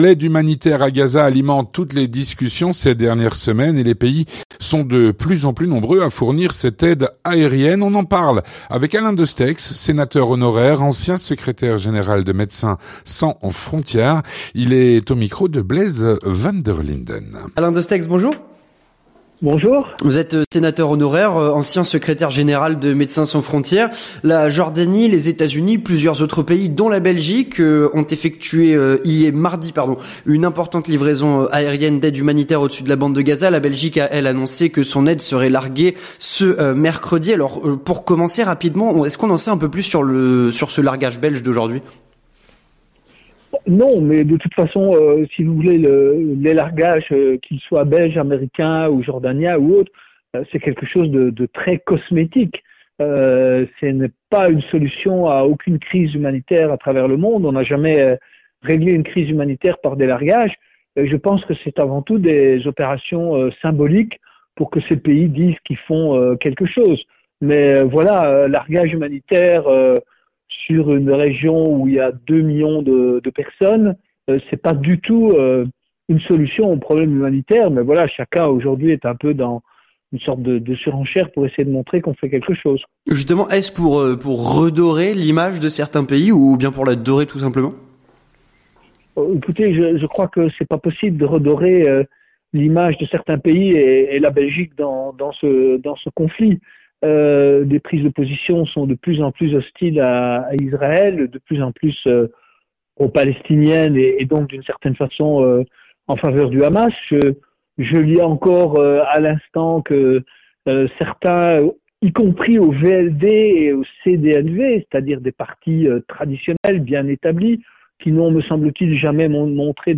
L'entretien du 18H - L’aide humanitaire à Gaza alimente toutes les discussions et de plus en plus de pays sont prêts à fournir cette aide.
Avec Alain Destexhe, sénateur honoraire, ancien secrétaire général de MSF.